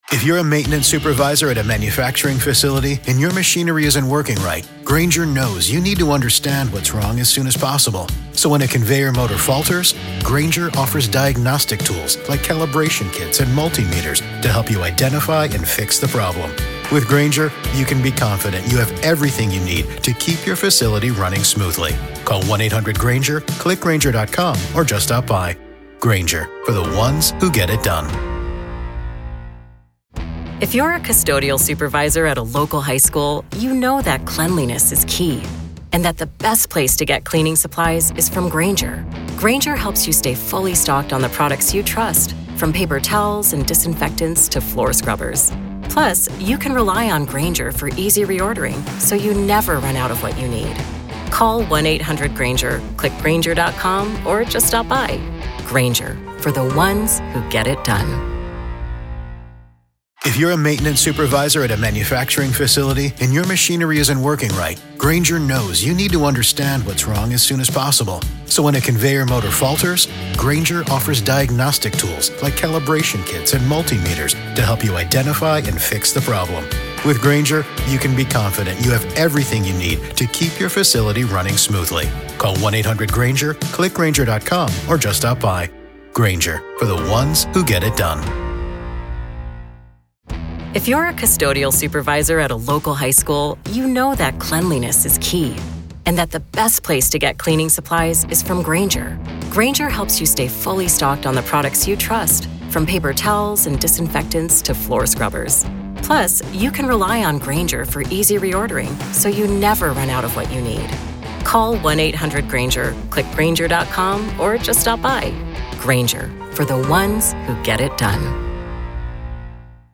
Legal analysts, forensic psychologists, and true crime experts have weighed in, and our panel explores those arguments in depth.